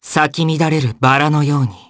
Fluss-Vox_Skill4_jp.wav